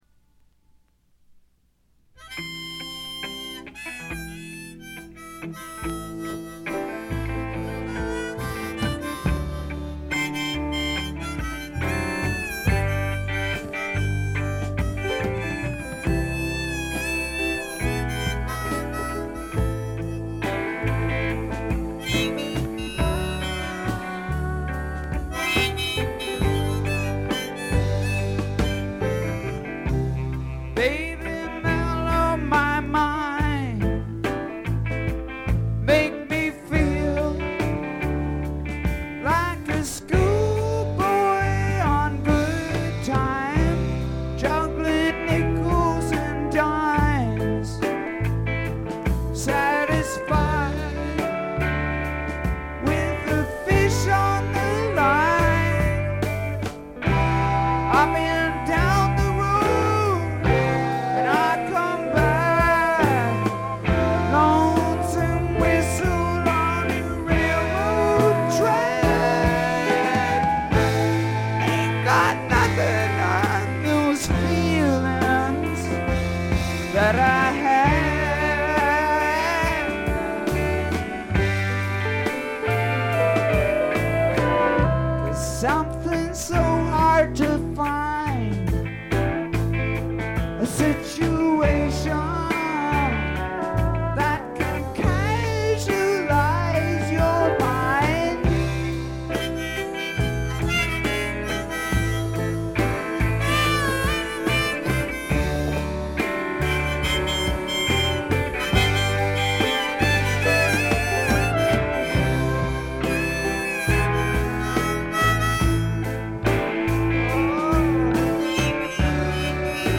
特に目立つノイズはありません。
試聴曲は現品からの取り込み音源です。
harmonica
steel guitar